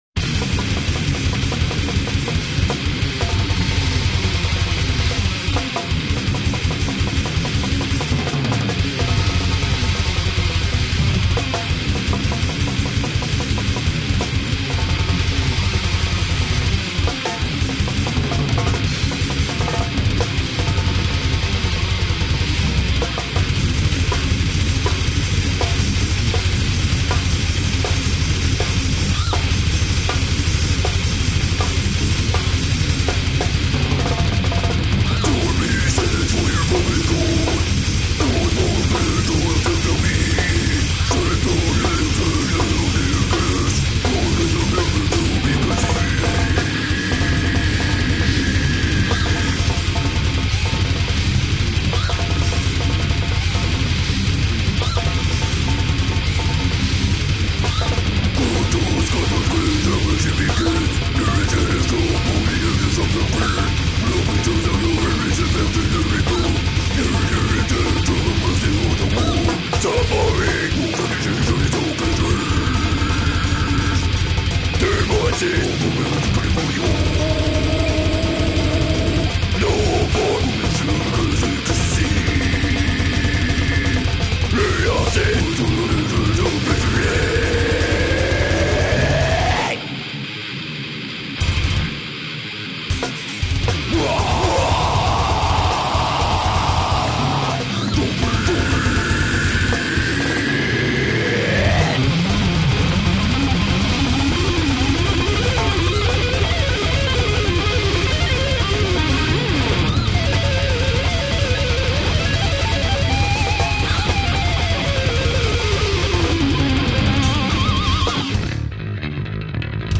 • brutal death metal